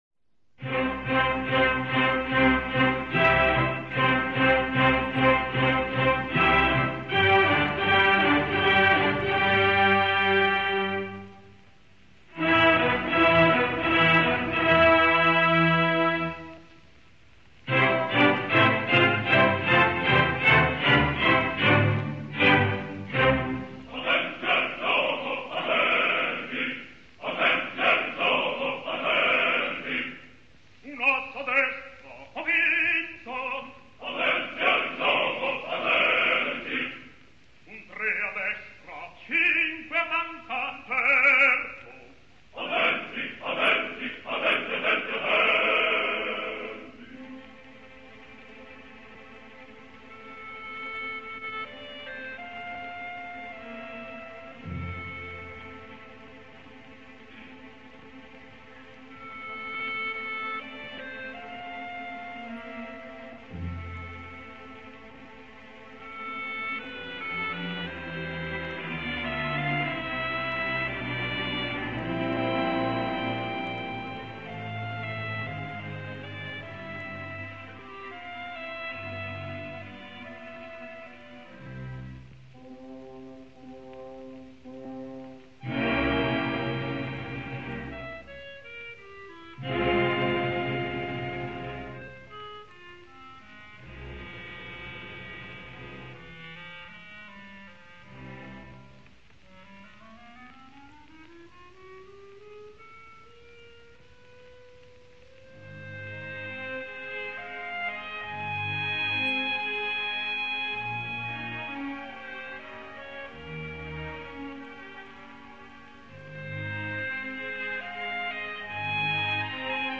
registrazione in studio.